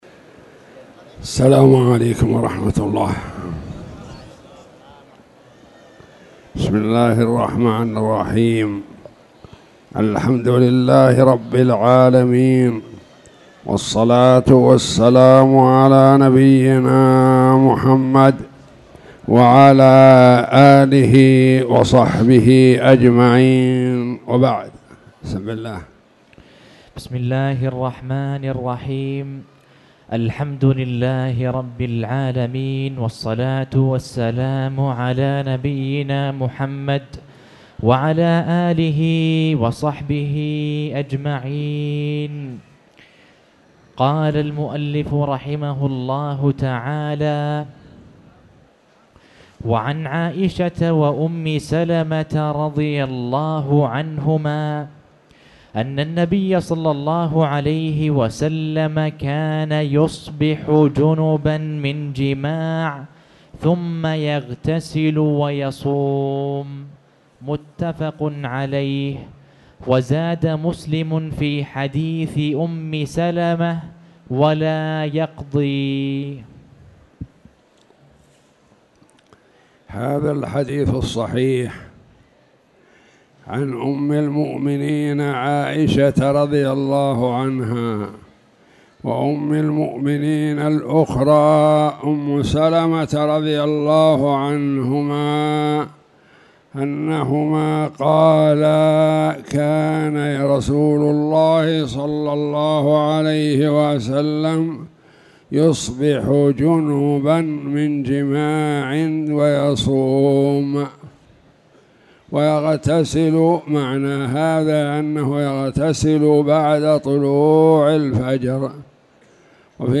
تاريخ النشر ٤ شوال ١٤٣٧ هـ المكان: المسجد الحرام الشيخ